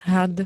had m. (hád)
Zvukové nahrávky niektorých slov